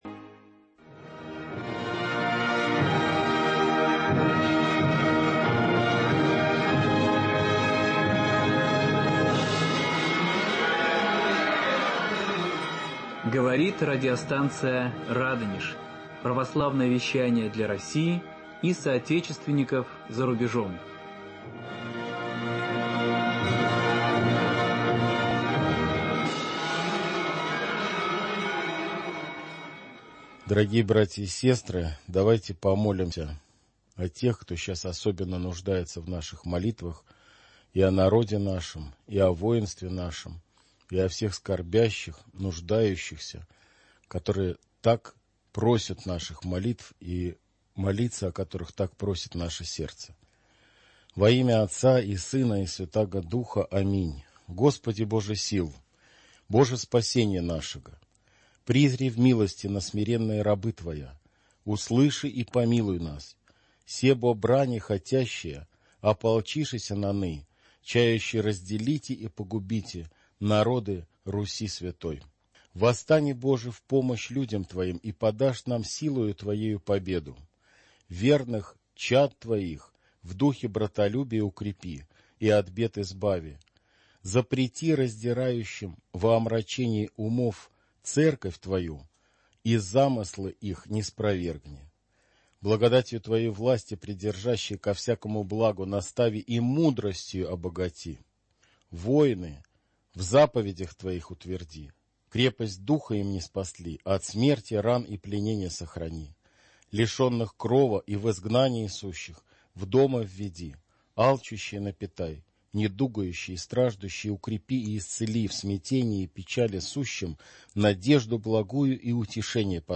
Не пропустите одно из самых ярких интервью этой осени на Радио «Радонеж»!